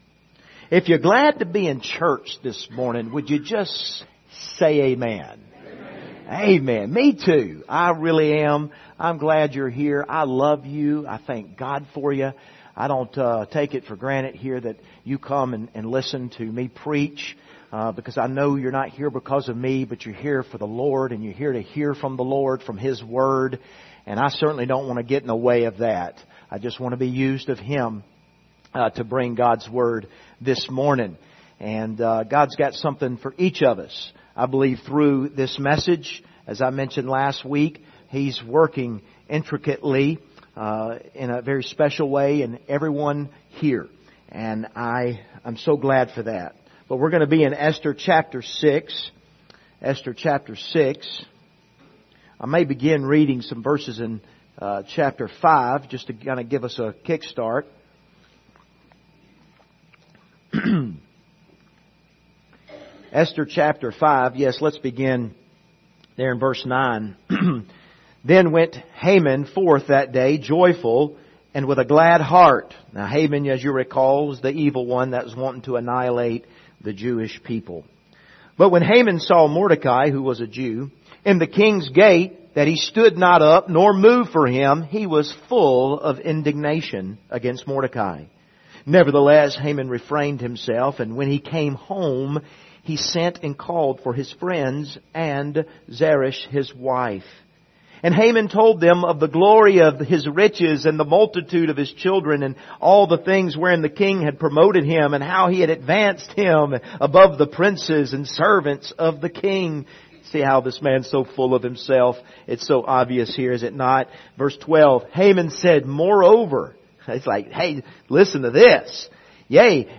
Passage: Esther 6 Service Type: Sunday Morning